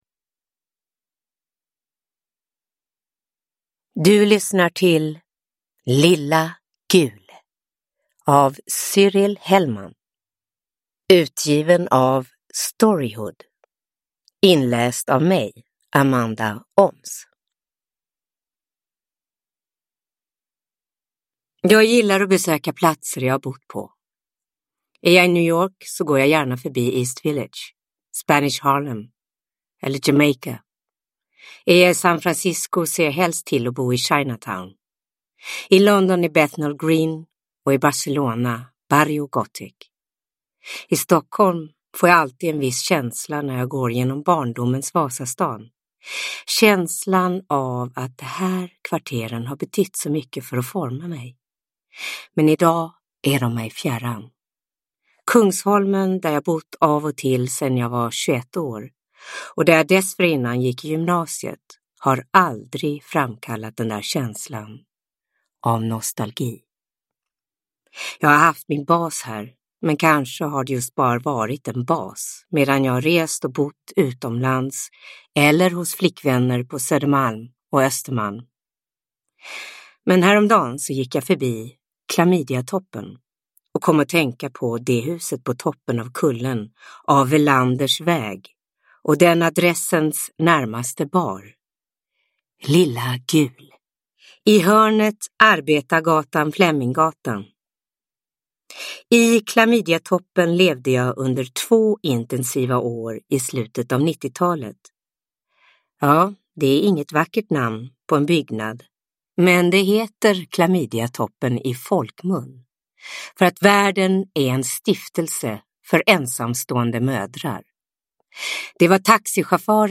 Lilla Gul – Ljudbok – Laddas ner